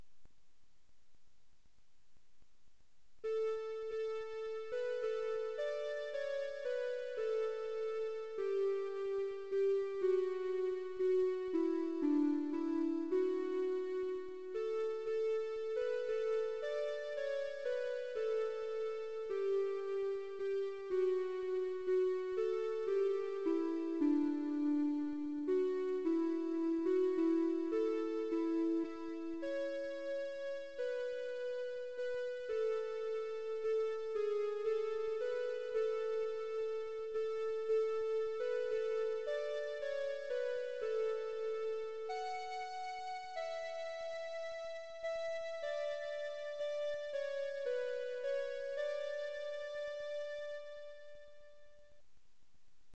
演奏1 mp3